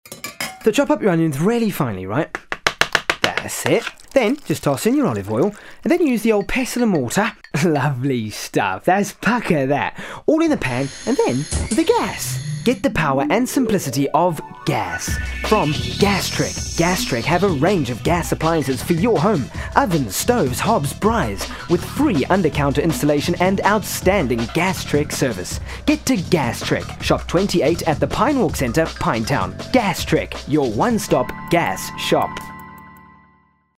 Gastrek radio ad
gastrek-radio-ad-2007.mp3